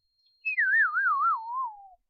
fall
effect fail fall game whistle sound effect free sound royalty free Sound Effects